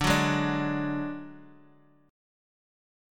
DMb5 chord